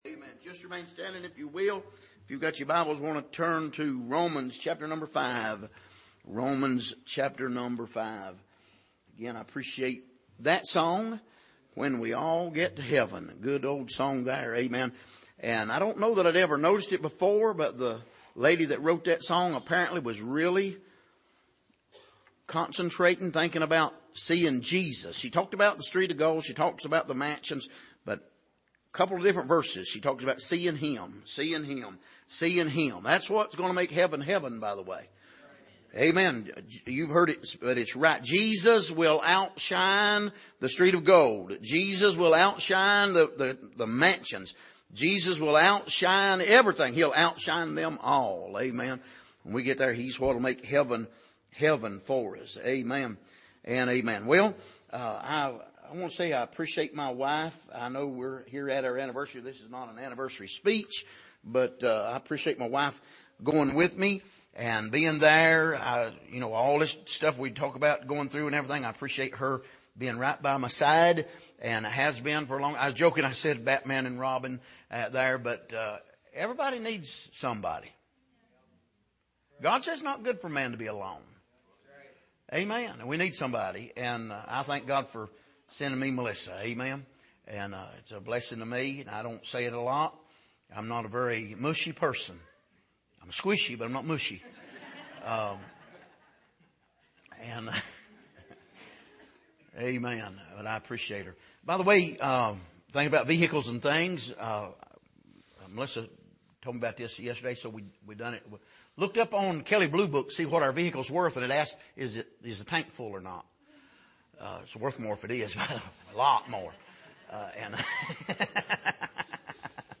Passage: Romans 5:1-11 Service: Sunday Morning